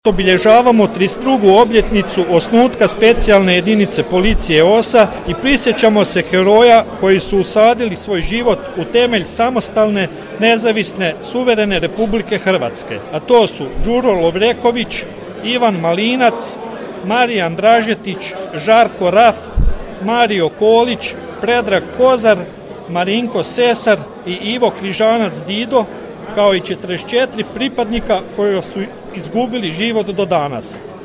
U srijedu, 15. ožujka 2023. godine, u Sisku u Hotelu Panonija svečano je obilježena 32. obljetnica osnutka Specijalne jedinice policije „OSA“.